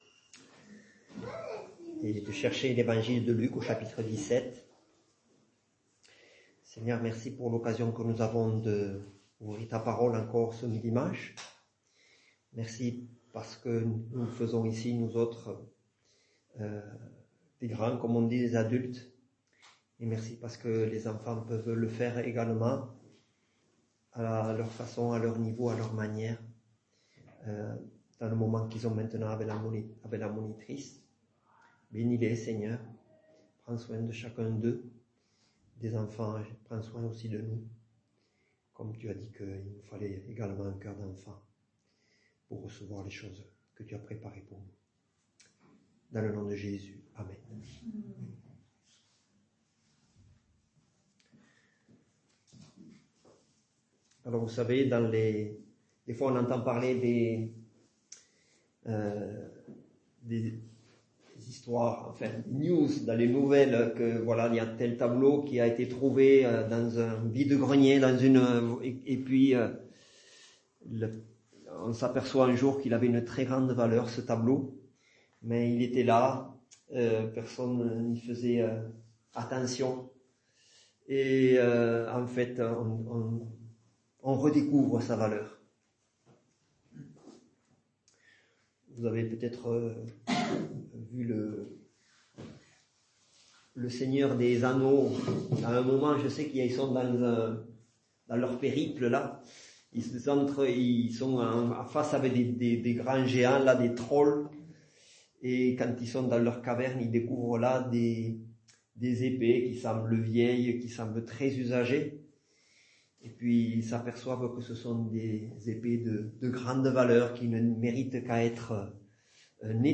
Culte du dimanche 29 septembre 2024 - EPEF